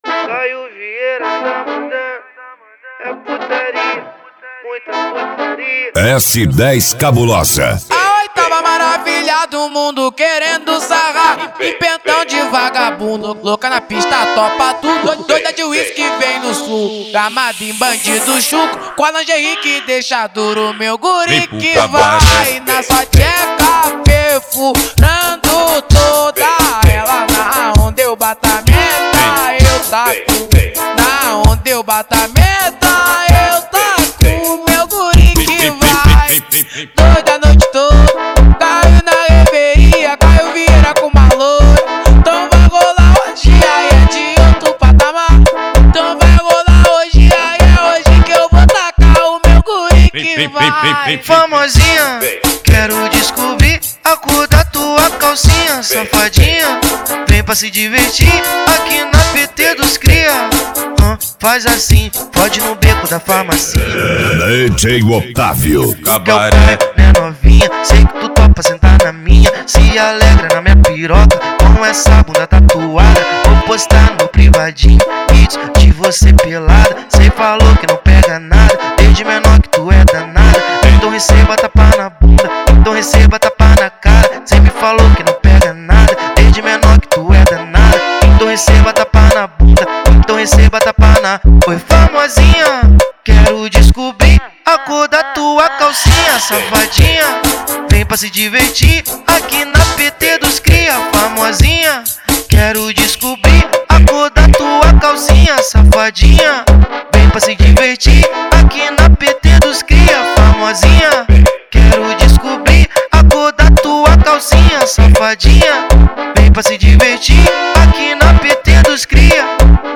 Eletro Funk